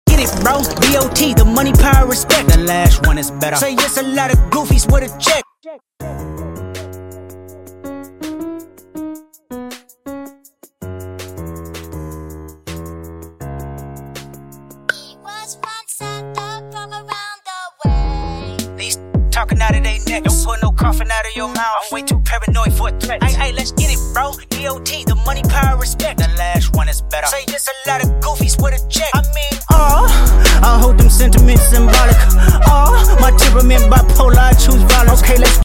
Quick 30 second tutorial on the beat